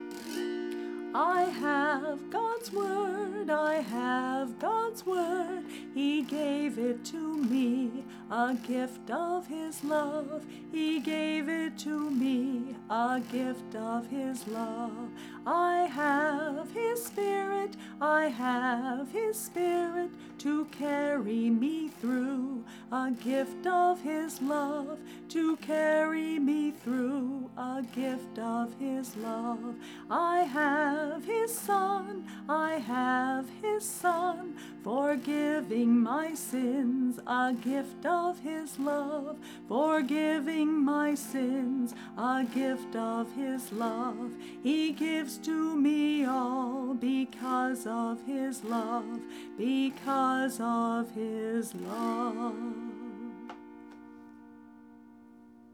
This entry was posted in Christian Music, Uncategorized and tagged , , , , , , , , , .